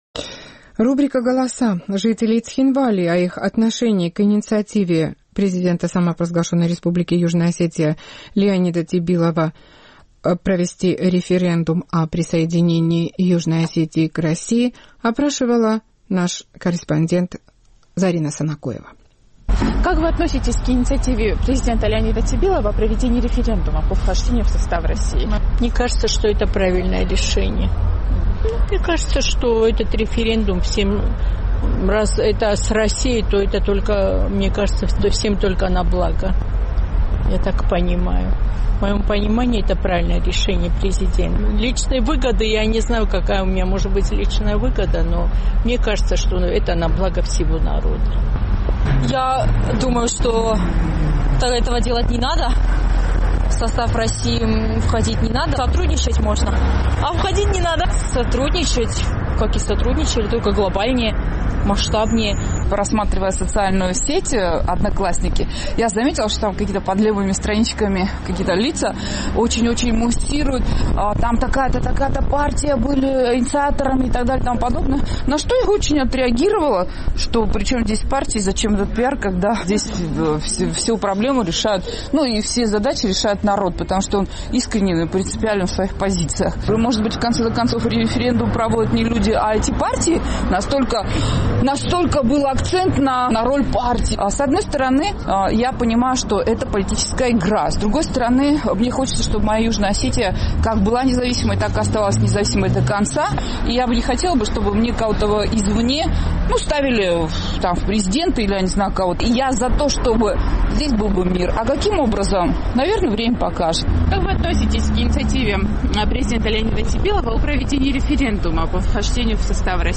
Наш югоосетинский корреспондент поинтересовалась мнением жителей Цхинвала по поводу инициативы Леонида Тибилова о проведении референдума по вхождению республики в состав России.